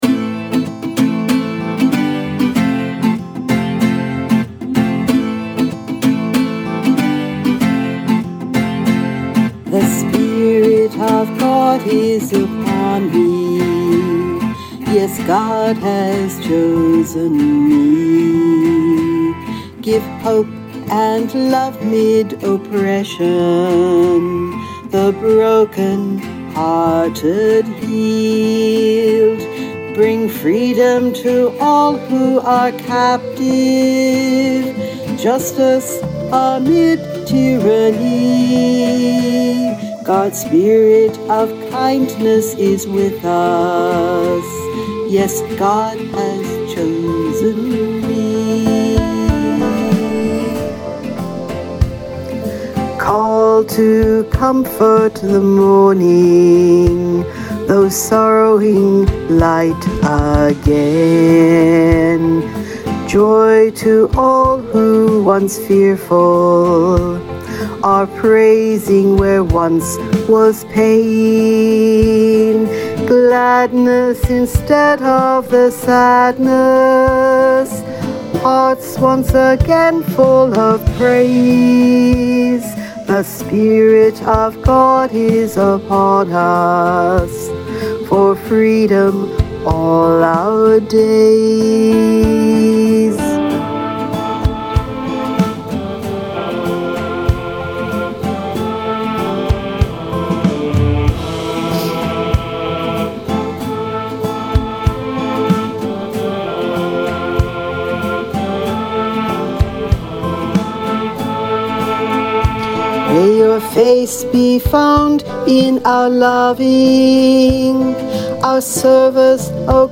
mp3 vocal